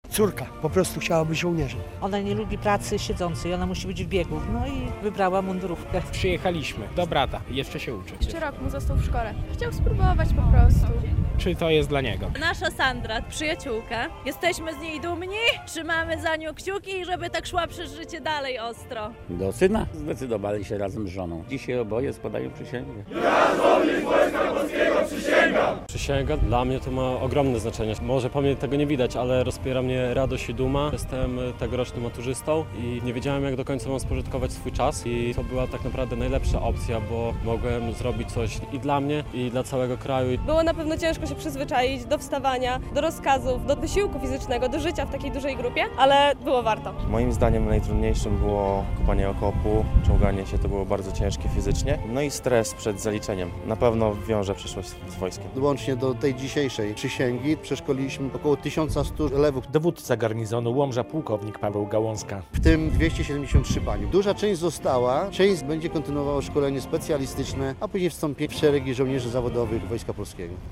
O prawie stu żołnierzy powiększyły się w sobotę (10.08.) w Łomży szeregi Wojska Polskiego. Przysięgę na sztandar 18. Łomżyńskiego Pułku Logistycznego złożyli elewi, którzy zasadnicze szkolenie przeszli w ramach projektu "Wakacje z wojskiem".